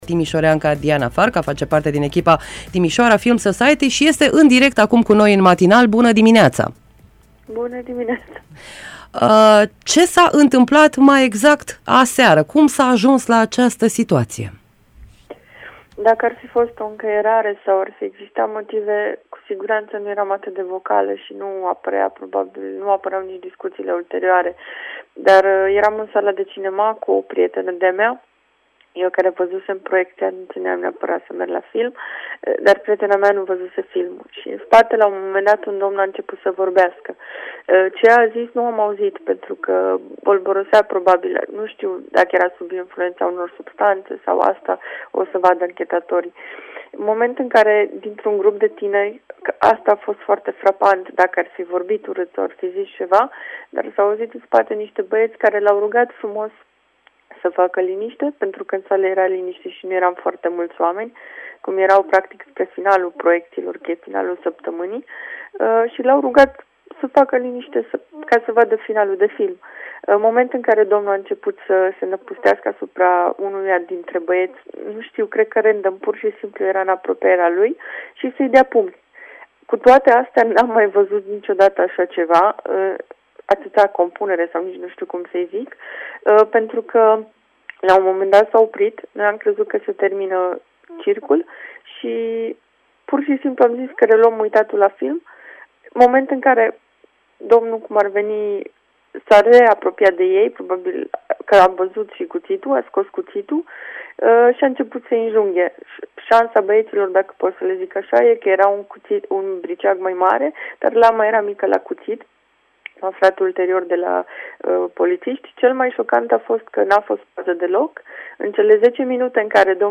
se afla în sala de cinema în care s-a petrecut incidentul și a povestit în „Bună dimineața, Vest!” ce a văzut la fața locului.